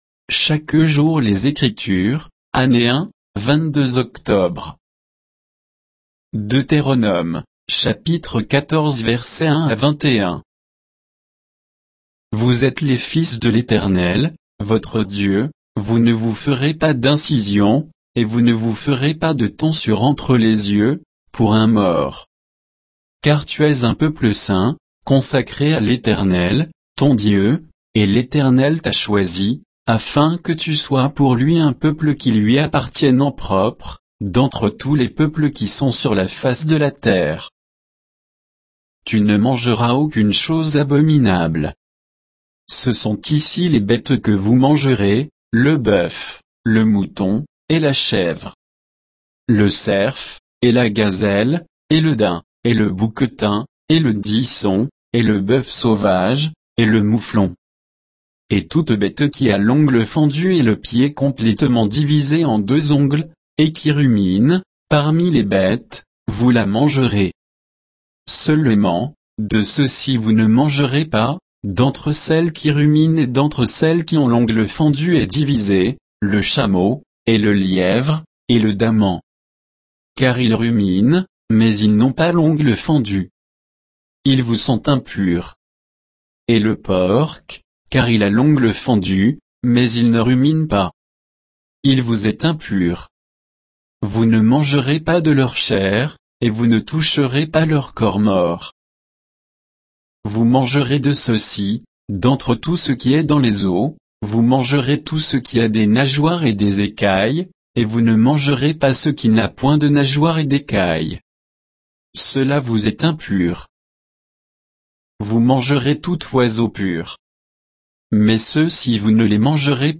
Méditation quoditienne de Chaque jour les Écritures sur Deutéronome 14, 1 à 21